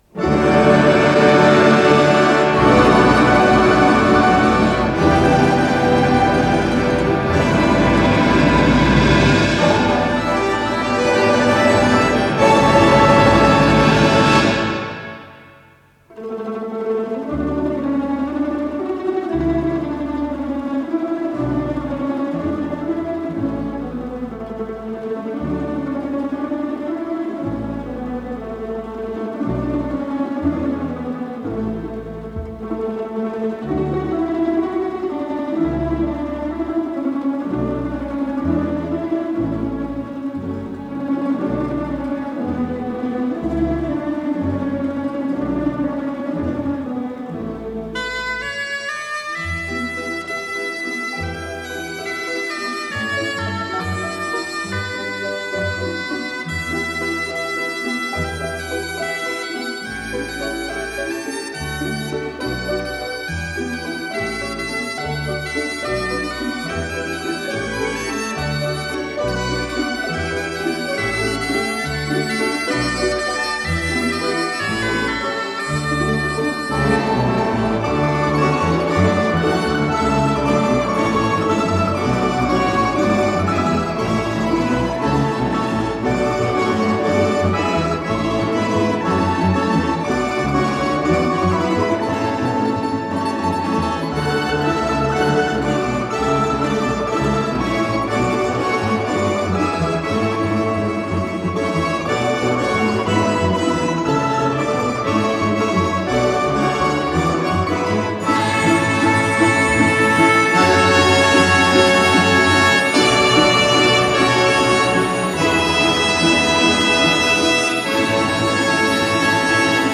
ре мажор